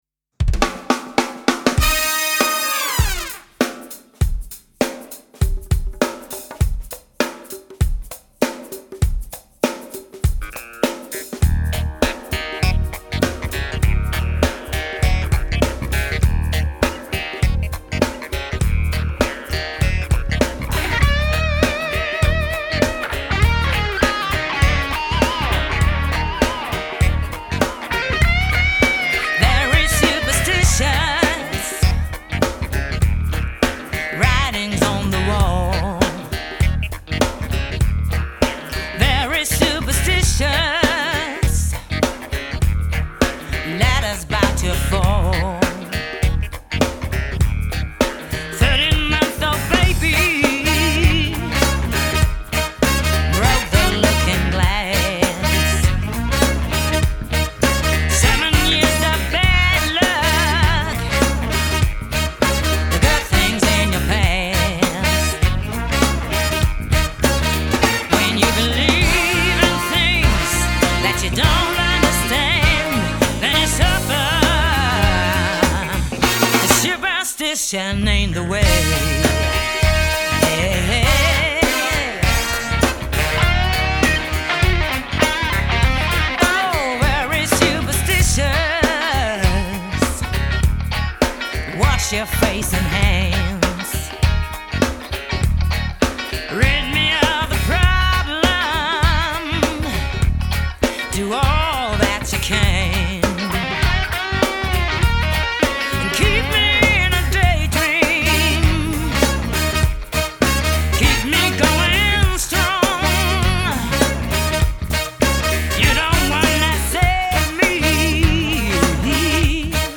Recording Session 2006:
Bass
Vocals
Saxophone
Trombone
Trumpet
Keyboards und Horn-Arrangements
Drums and Percussion
Guitars